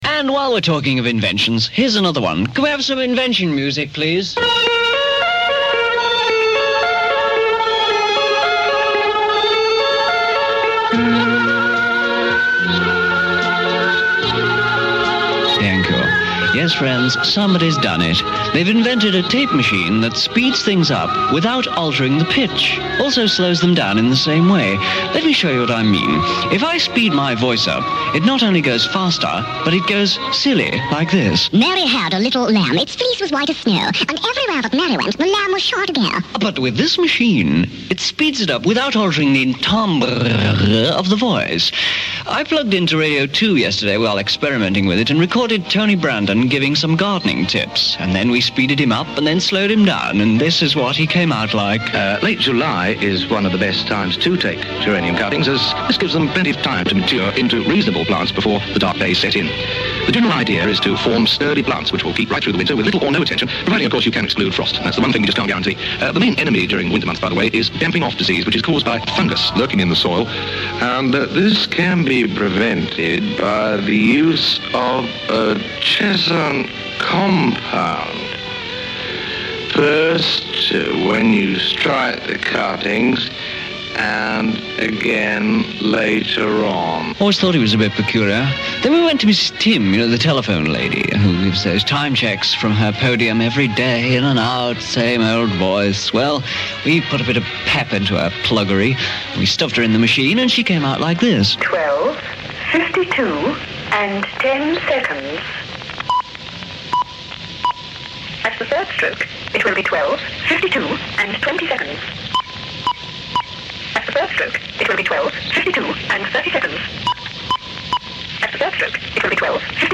Ev demonstrates the Eltro Tempophon in 1972